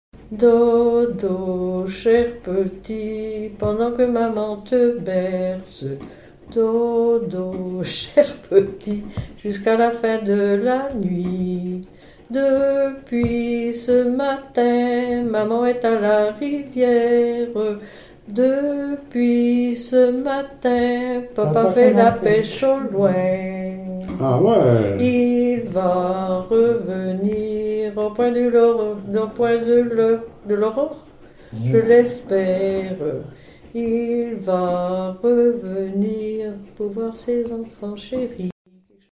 berceuse
Enquête Douarnenez en chansons
Pièce musicale inédite